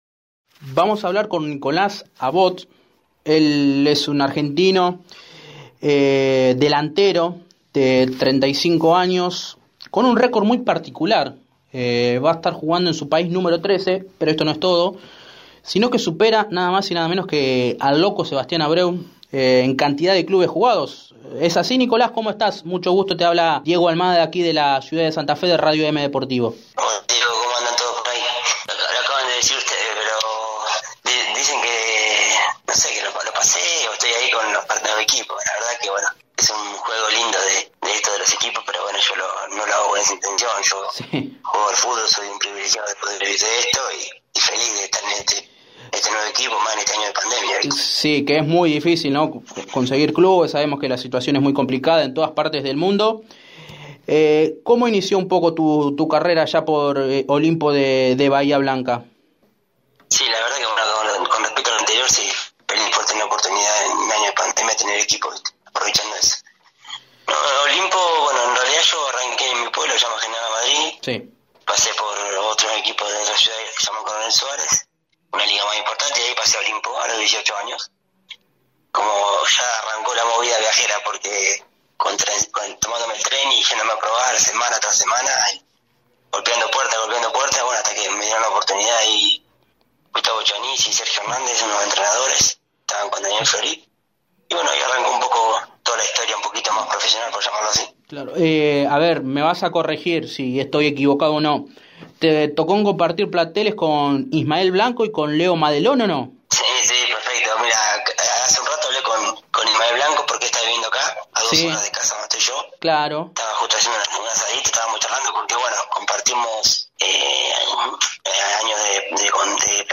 En diálogo con Radio EME Deportivo